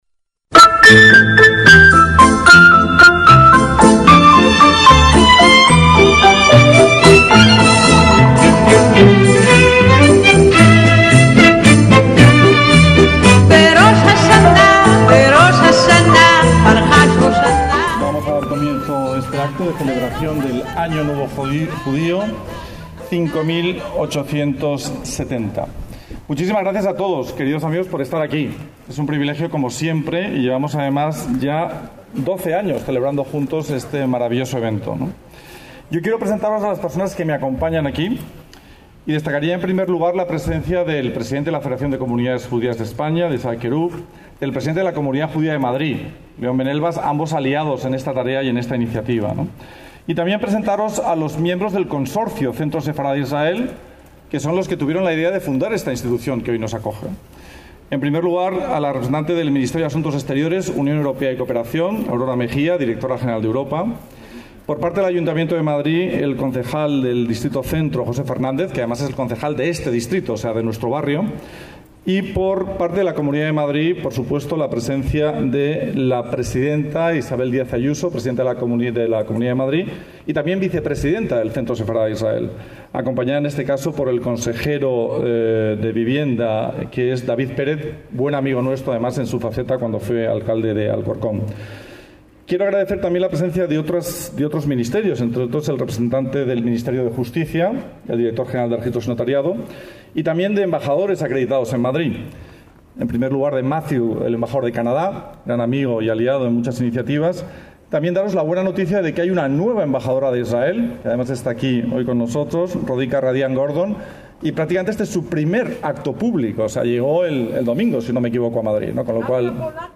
ACTOS EN DIRECTO - Con la presencia de numerosas autoridades e invitados, el 24 de septiembre de 2019 tuvo lugar en la sede del Centro Sefarad Israel en Madrid la ya tradicional Celebración del Año Nuevo Judío (Rosh Hashaná), 5780 según el calendario hebreo.